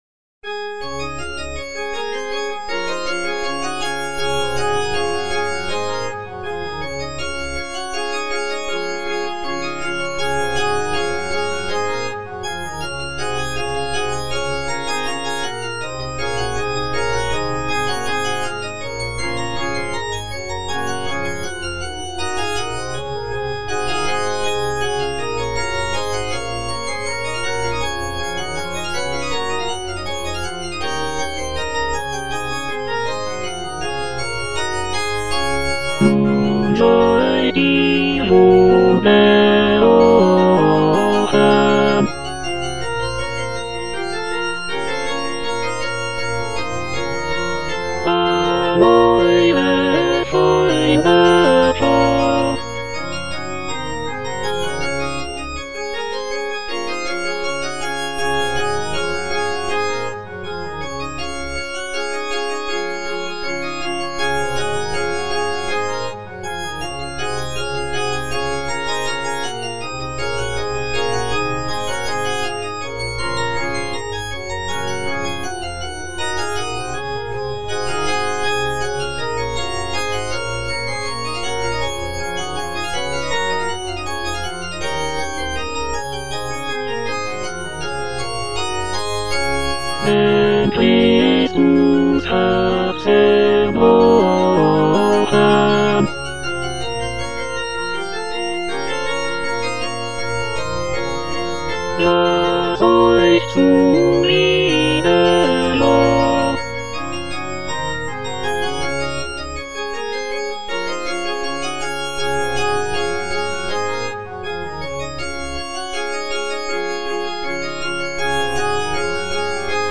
Choralplayer playing Christmas Oratorio BWV248 - Cantata nr. 6 (A = 415 Hz) by J.S. Bach based on the edition Bärenreiter BA 5014a
J.S. BACH - CHRISTMAS ORATORIO BWV248 - CANTATA NR. 6 (A = 415 Hz) 64 - Nun seid ihr wohl gerochen - Tenor (Emphasised voice and other voices) Ads stop: auto-stop